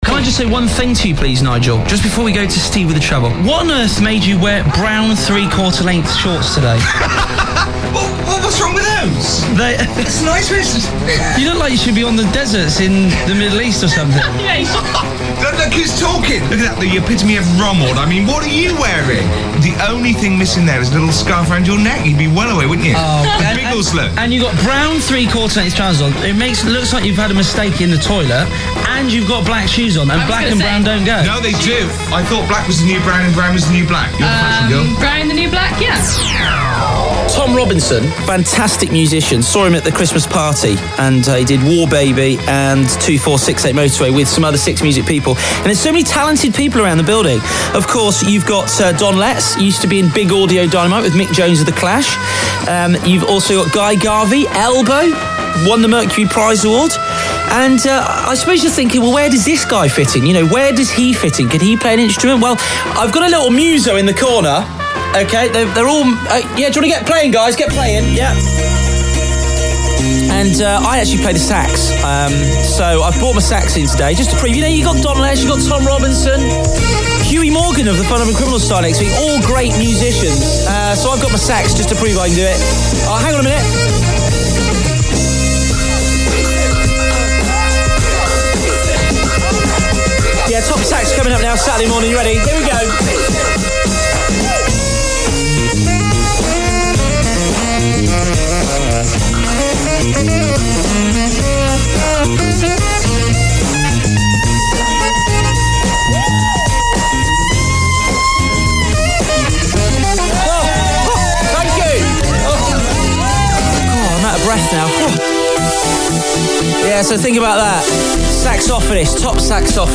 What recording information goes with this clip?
broadcasting at various stations around the U.K, and some pilot shows thrown in for fun!